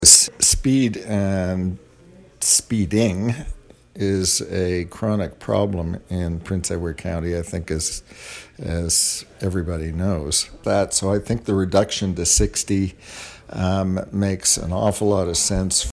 Mayor Steve Ferguson says it is a residential area and with more cars coming and going there is an increased danger.